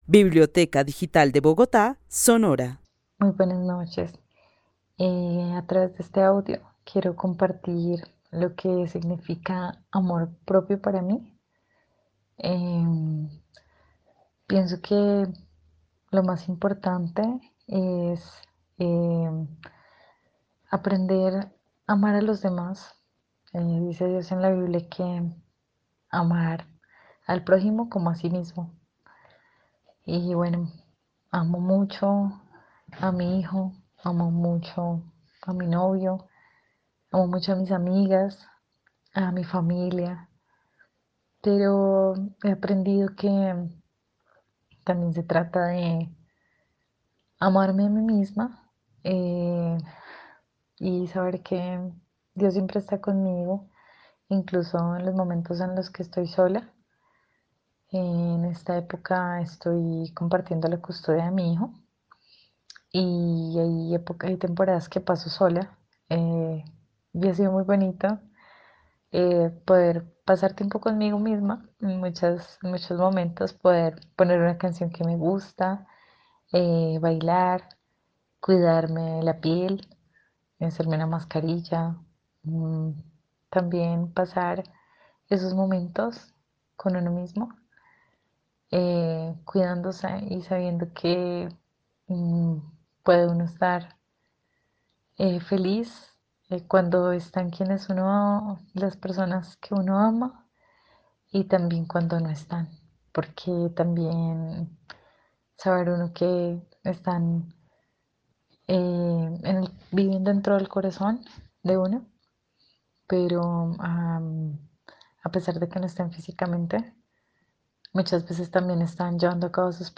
Narración oral de una mujer que vive en la ciudad de Bogotá, y para quien el amor propio está relacionado con la cita de la Biblia: "amar al prójimo como a sí mismo". En su reflexión menciona vivencias personales que la han llevado a un aprendizaje sobre disfrutar el tiempo a solas.
El testimonio fue recolectado en el marco del laboratorio de co-creación "Postales sonoras: mujeres escuchando mujeres" de la línea Cultura Digital e Innovación de la Red Distrital de Bibliotecas Públicas de Bogotá - BibloRed.